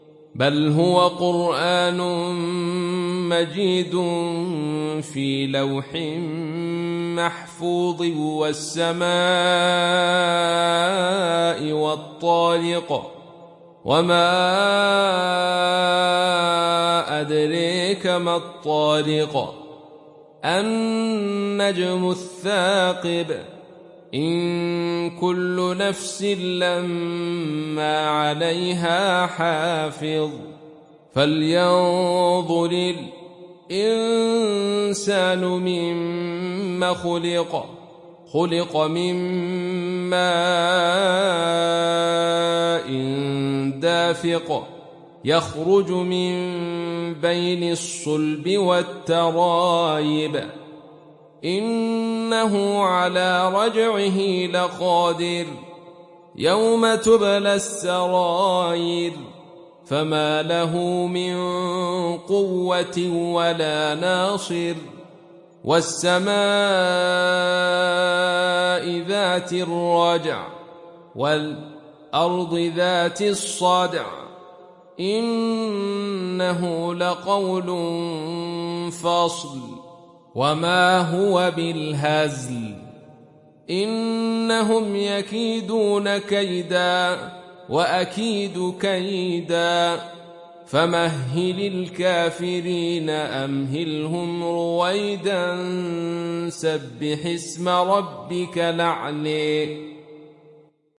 সূরা আত-ত্বারেক ডাউনলোড mp3 Abdul Rashid Sufi উপন্যাস Khalaf থেকে Hamza, ডাউনলোড করুন এবং কুরআন শুনুন mp3 সম্পূর্ণ সরাসরি লিঙ্ক